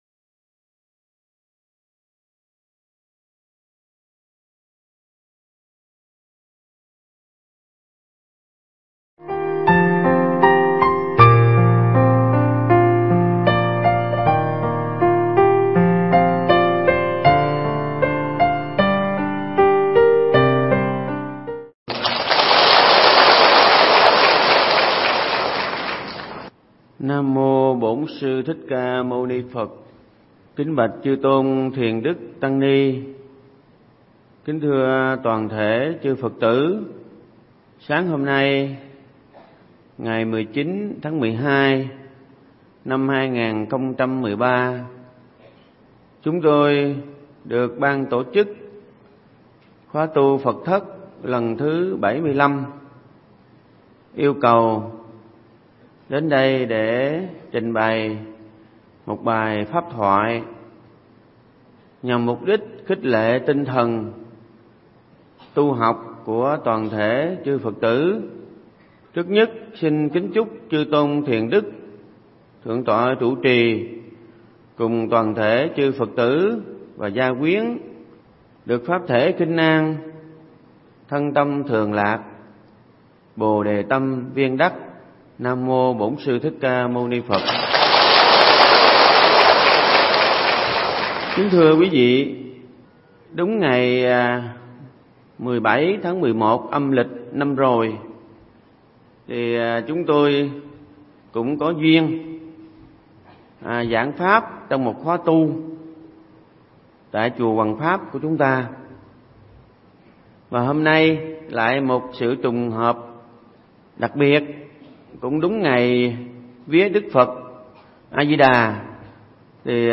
Nghe Mp3 thuyết pháp Phật Giáo và Môi Trường
Mp3 pháp thoại Phật Giáo và Môi Trường